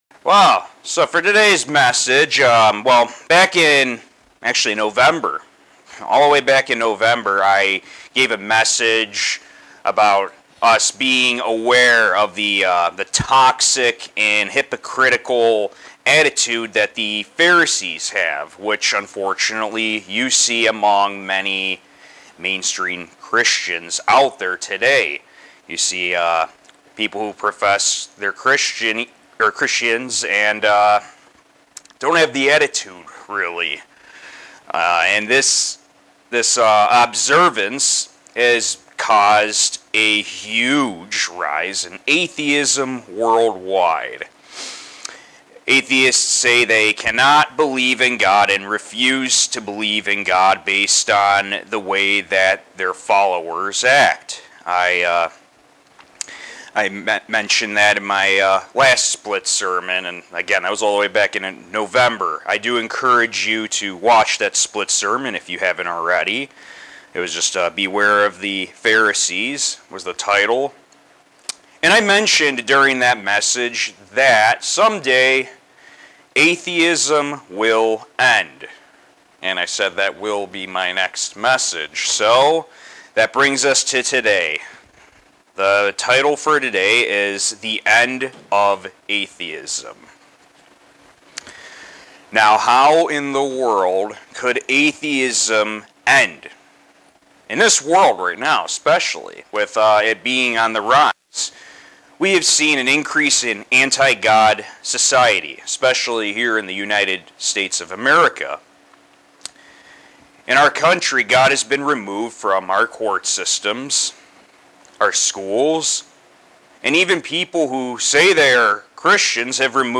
On day atheism will be a thing of the past. This sermon will explain how this can happen,